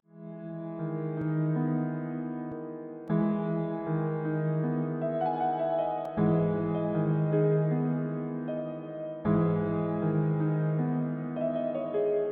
Citrus_Piano.wav